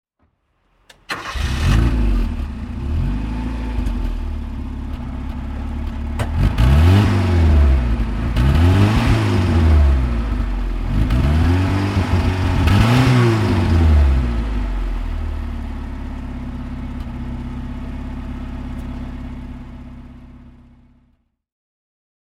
Toyota_Corolla_1968.mp3